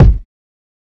Kicks
Metro Big Kick.wav